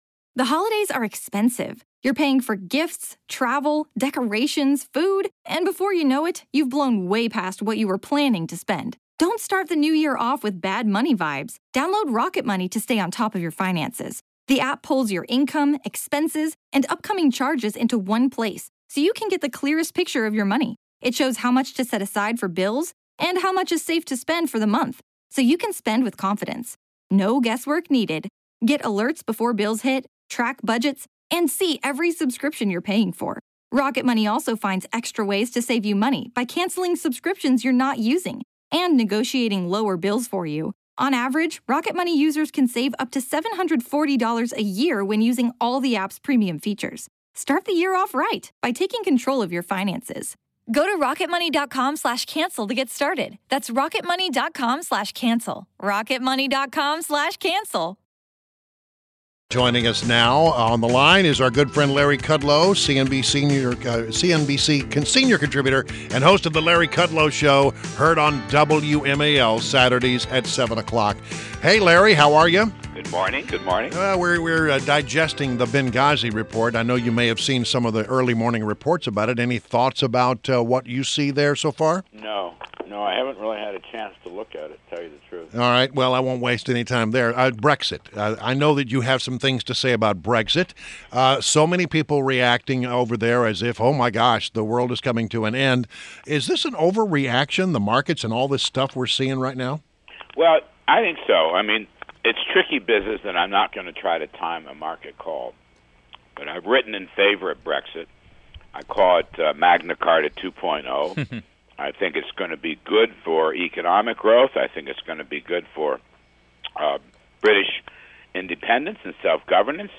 WMAL Interview - Larry Kudlow - 06.28.16